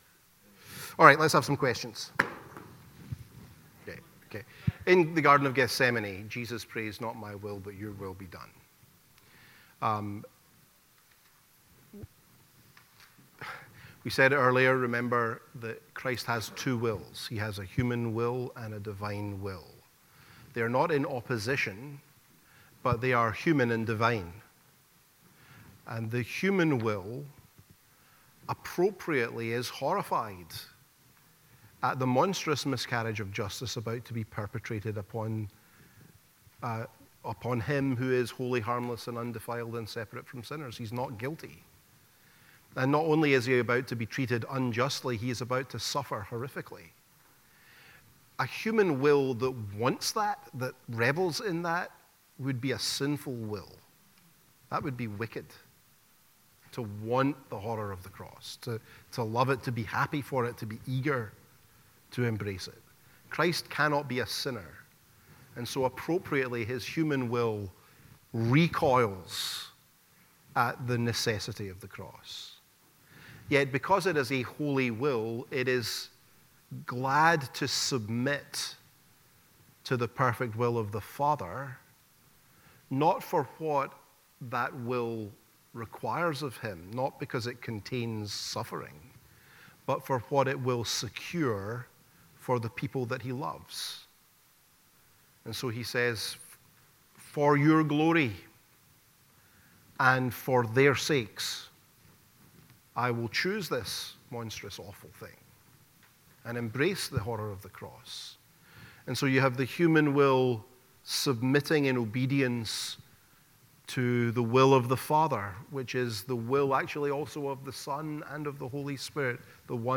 Discover a wealth of resources at First Presbyterian Church of Jackson, Mississippi.
The-Doctrine-of-the-Trinity-Lecture-4-Question-and-Answer-Session.mp3